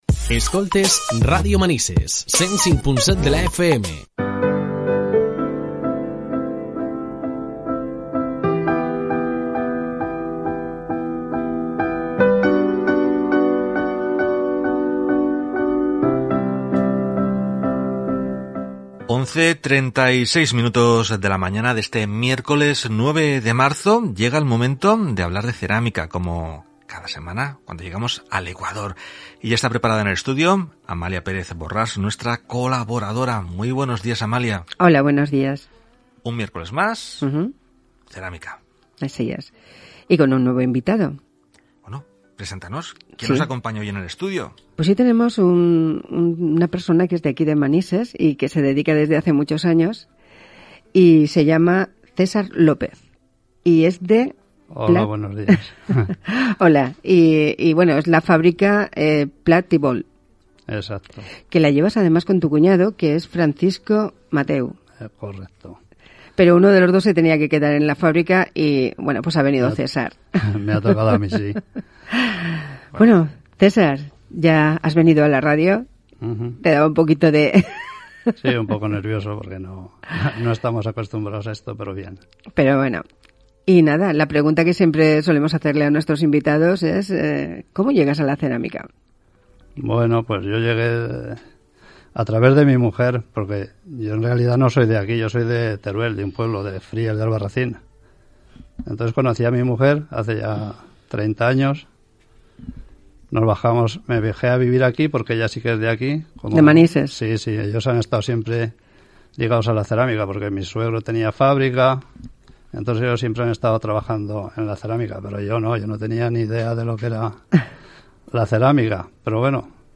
Entrevista en RADIO MANISES 105.7
entrevista-radio-manises.mp3